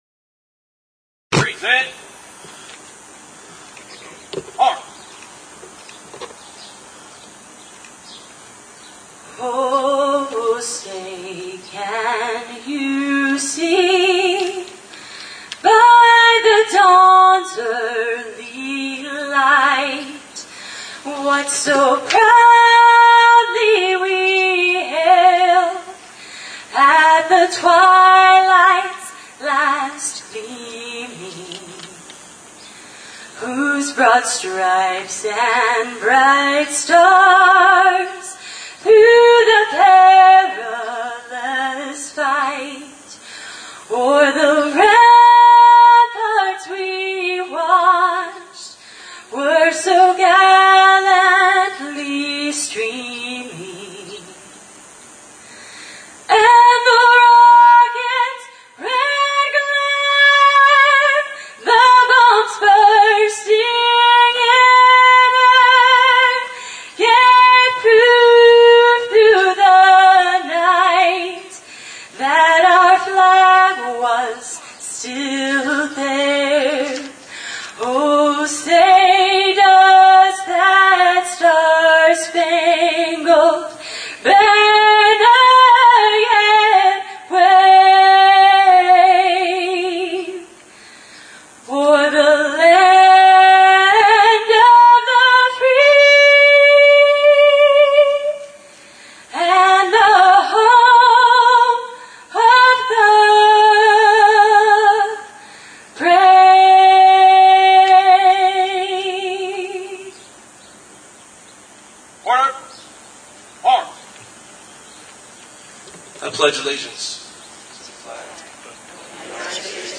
Please listed to the entire audio track of this HERE since this is unedited there are some areas where there is video on the screen but you will only hear the words.
The memorial presentation was held in front of the Glendale Public Safety Memorial
This morning we joined with The Glendale Fire Department to honor the brave individuals who made the ultimate sacrifice on 9/11 at a ceremonial event on Wednesday, September 11 The event will took place at the dramatic Glendale Public Safety Memorial located at Glendale Civic Center Plaza, 5750 W. Glenn Drive.